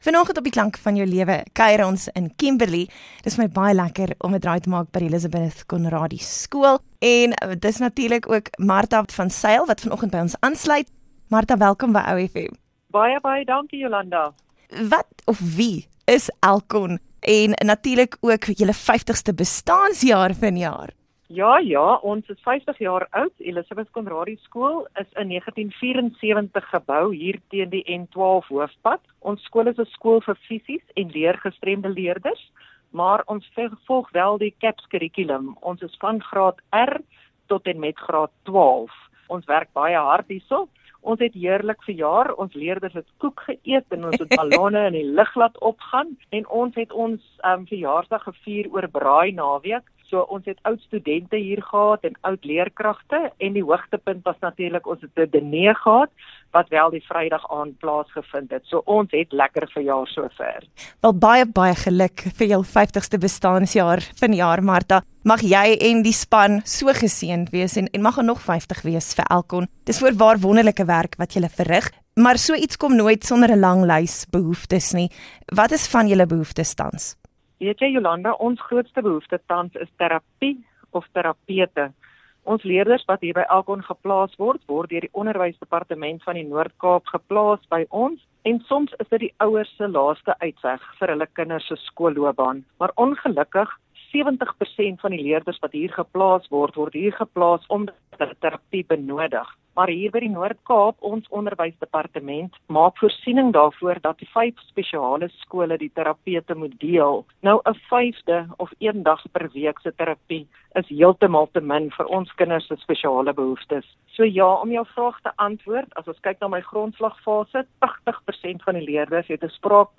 11 Oct Onderhoud 2: Kelrn Bergfietswedren begunstigde: Elizabeth Conradie School